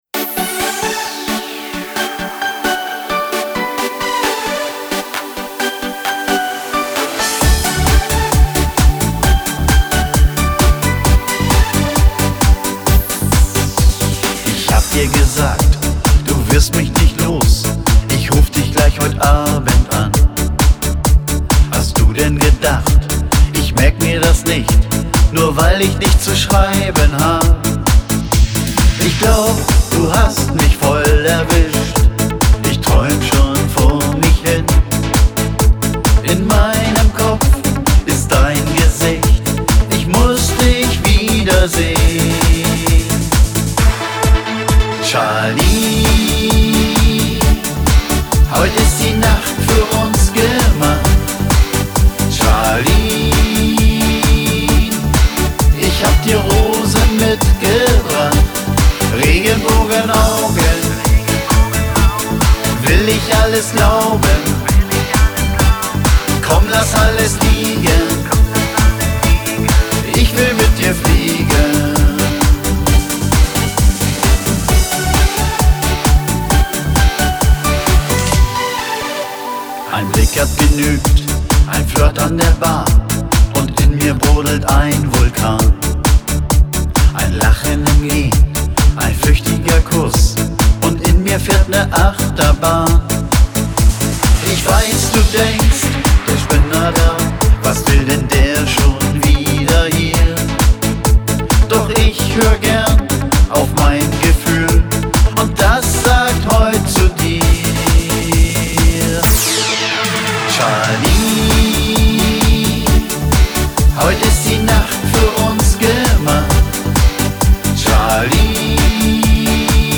Der Gentleman des Popschlagers !
• Sänger/in